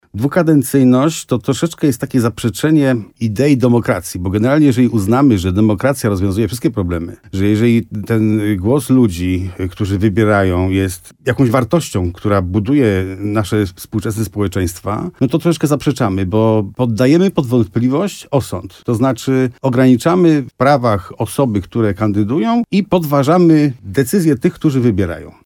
Zwolennikiem dwukadencyjności nie jest wójt gminy Dobra Benedykt Węgrzyn, gość programu Słowo za słowo w RDN Nowy Sącz.